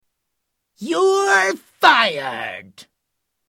Cartoon Voice- You're fired